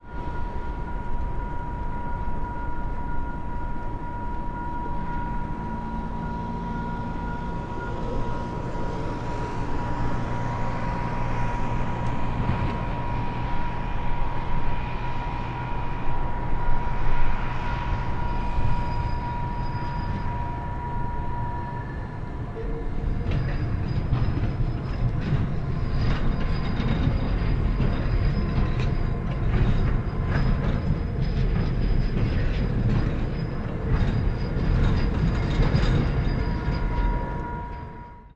布达佩斯 " 布达佩斯大桥交通
描述：放大H1。汽车和电车经过自由桥布达佩斯。汽车走向桥梁连接会产生咕噜声。
Tag: 电车 交通 传递 电车